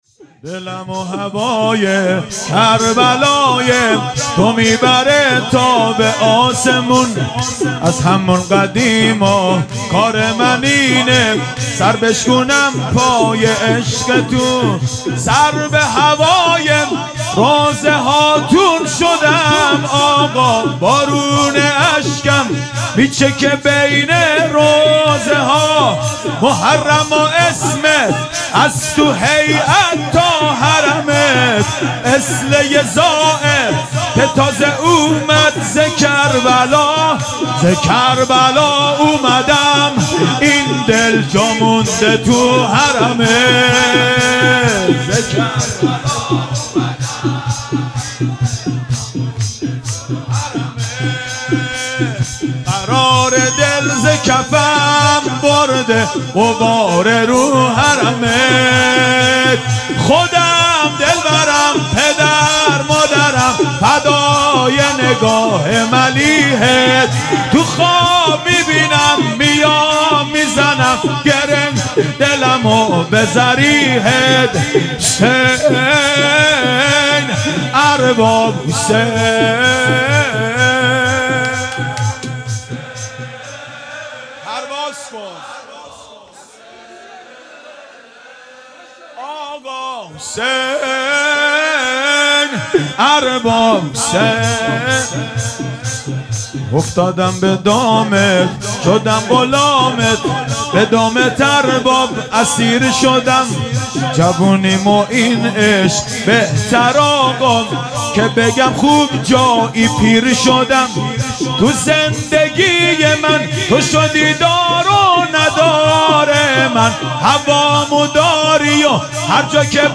و جلسه ی هفتگی ۲۹ آذر ۱۳۹۷ هیئت حسین جان گرگان
شور